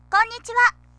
綾はしゃべったりもします。